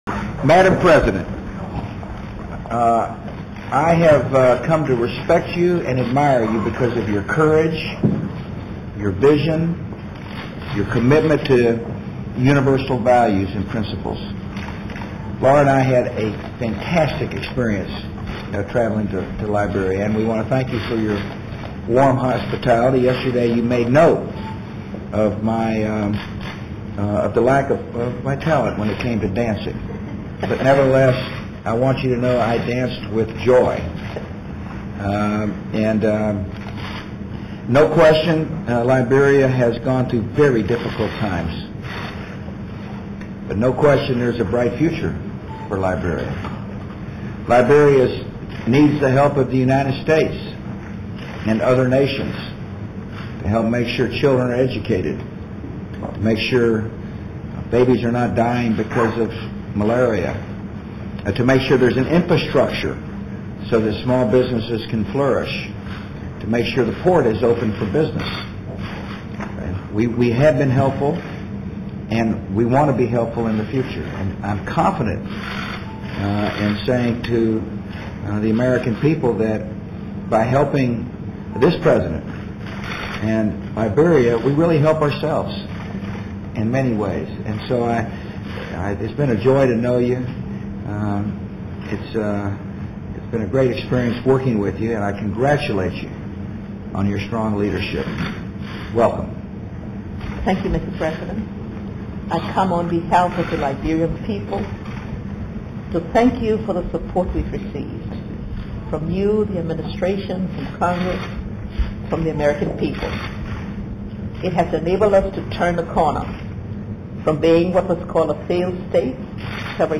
U.S. President George W. Bush speaks with President Johnson Sirleaf of Liberia in the Oval Office